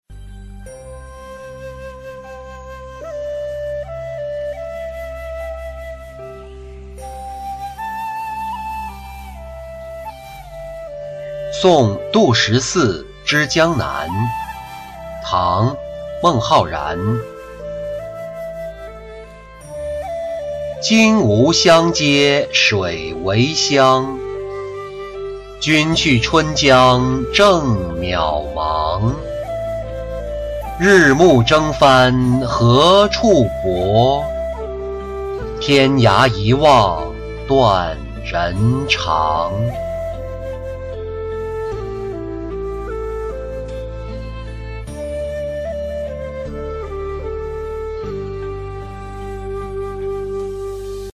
送杜十四之江南-音频朗读